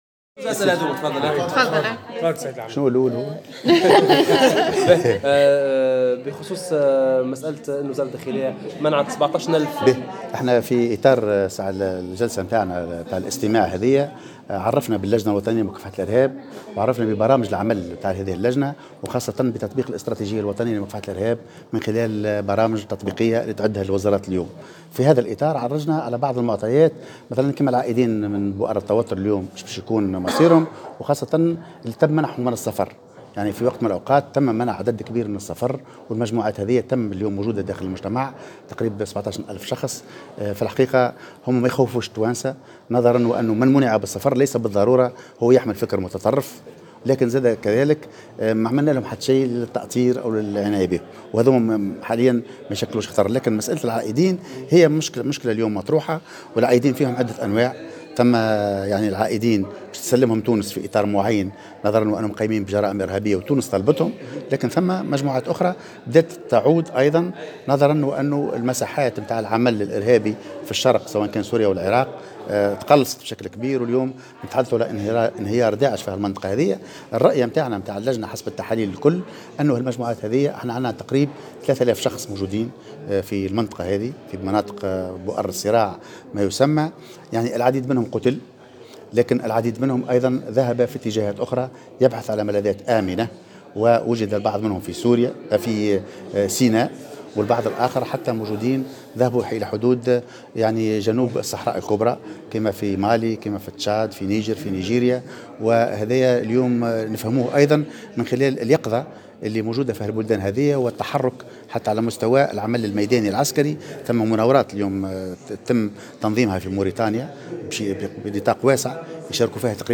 وأوضح في تصريح لمراسل "الجوهرة أف أم" على هامش جلسة استماع ضمن لجنة الأمن والدفاع بمجلس نواب الشعب أنه تم تسجيل هذا الرقم في الفترة الممتدة بين 2011 واكتوبر 2018 دون اعتبار 17 ألف شخص تم منعهم من السفر نحو مناطق الصراع للالتحاق بتنظيمات ارهابية.